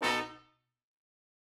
GS_HornStab-B7b2sus4.wav